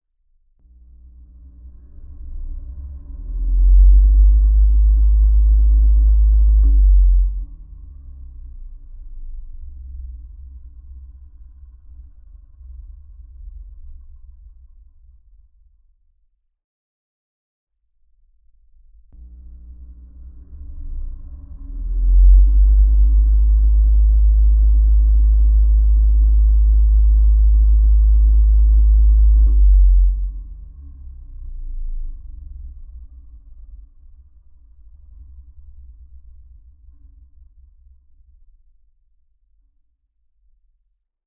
Originally designed for seismic measurements, it can be used with regular field recording equipment to capture very faint vibrations in various materials and even soil.
bathroom-fan-a-geophone.mp3